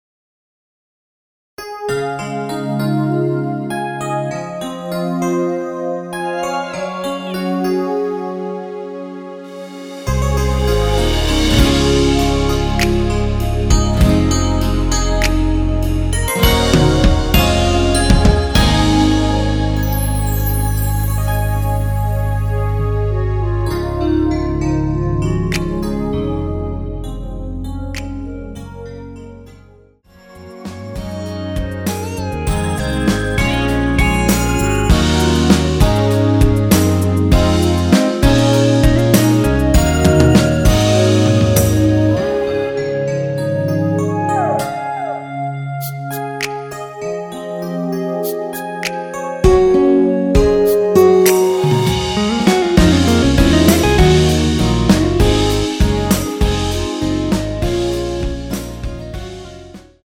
원키에서(-1)내린 2절 삭제한 멜로디 포함된 MR입니다.
Db
앞부분30초, 뒷부분30초씩 편집해서 올려 드리고 있습니다.
중간에 음이 끈어지고 다시 나오는 이유는